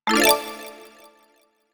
textmessage.ogg